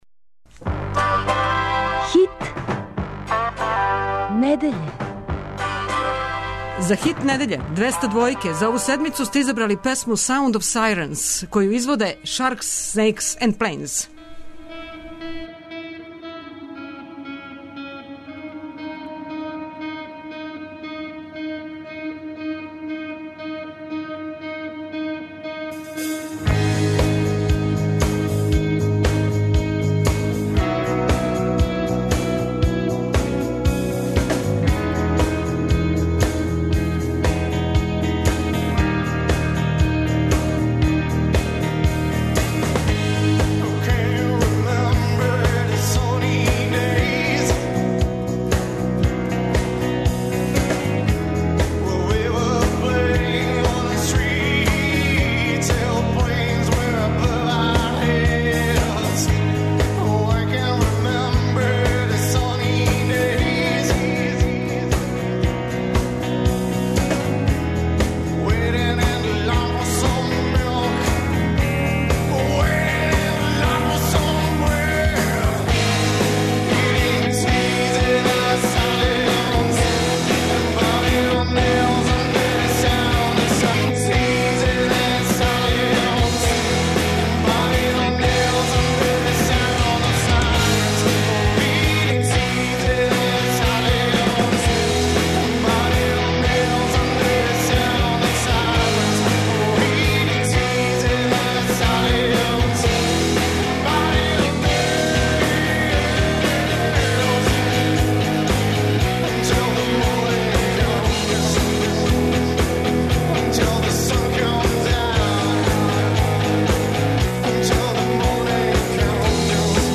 Као и сваке суботе и ове је на програму Финале Хита недеље од 19 до 21 сат. Пролази 15 песама у три категорије.